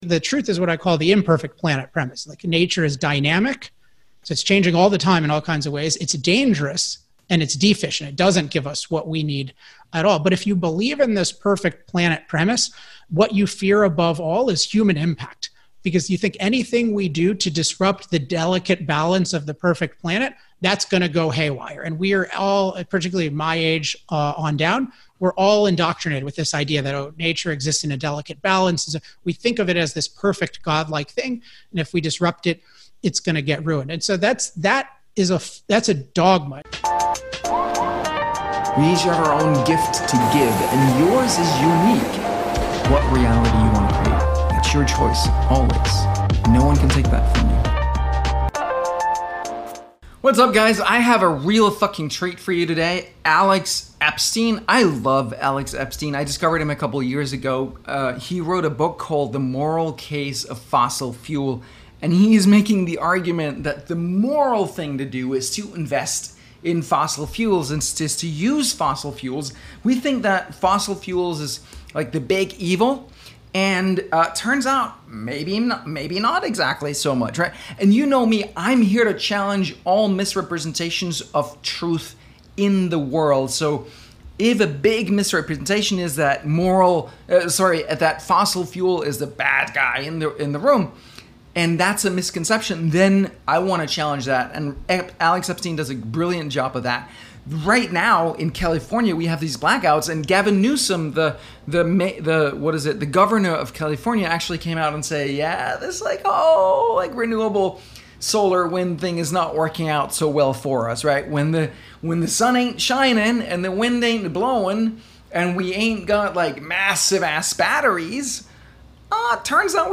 A Moral Case for Fossil Fuels An Interview with Alex Epstein